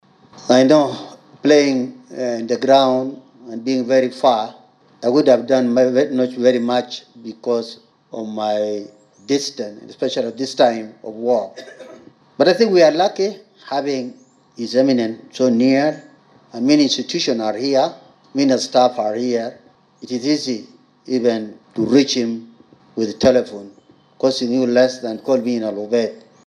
Bishop-Tombe-Trille-outgoing-president-of-SSSCBC.mp3